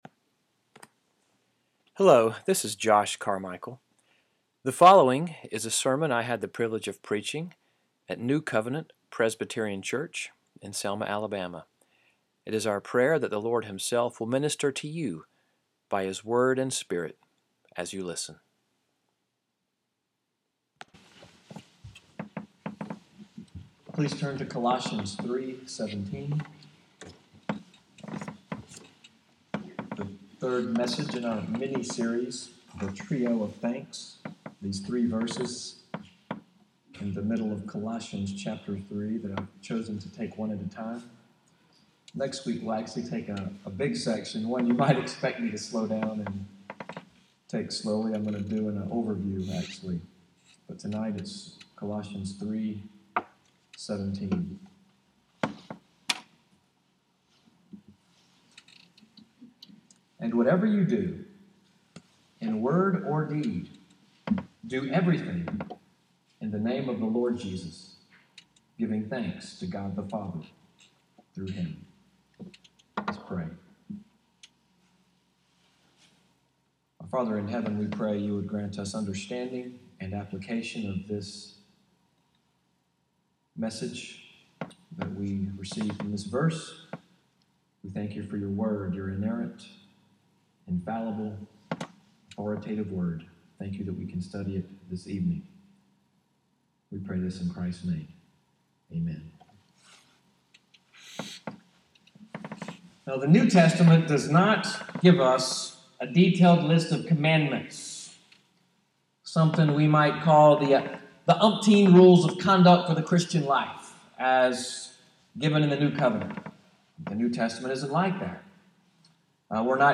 EVENING WORSHIP at NCPC, sermon audio, The Trio of Thanks: “Anything Worth Doing,” March 26, 2017